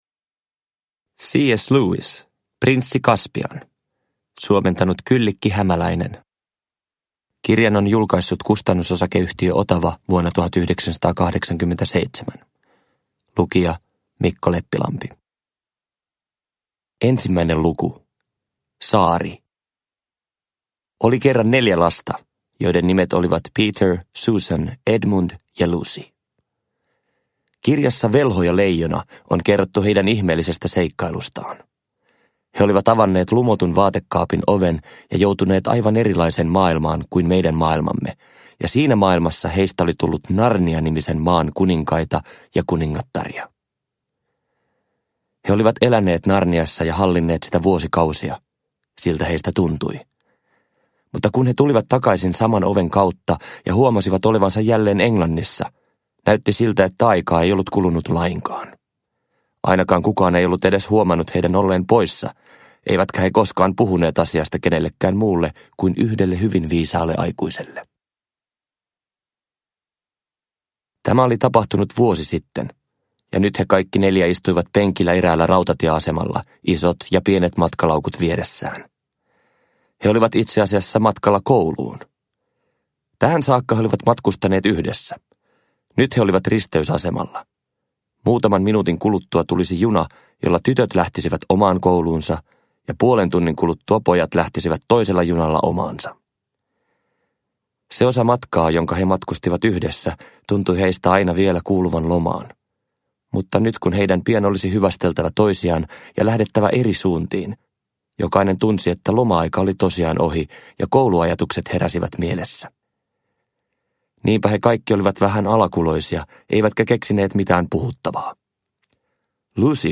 Prinssi Kaspian – Ljudbok – Laddas ner
Uppläsare: Mikko Leppilampi